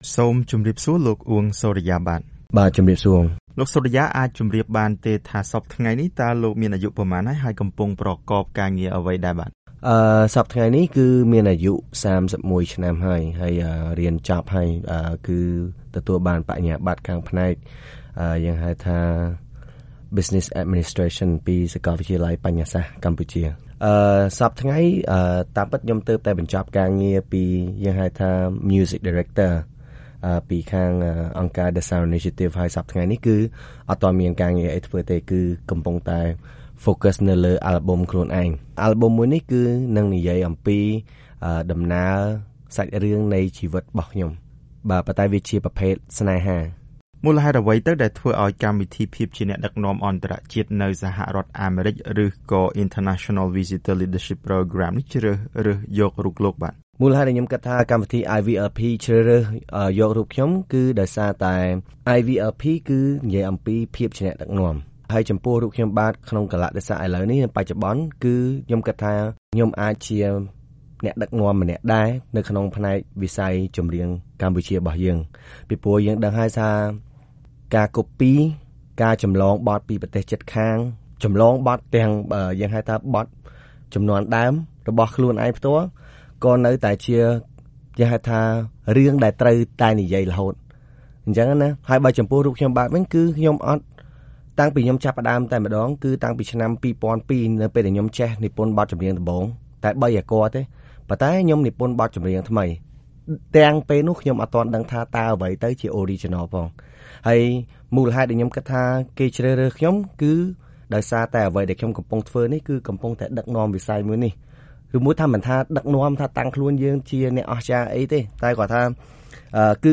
បទសម្ភាសន៍VOA